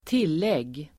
Uttal: [²t'il:eg:]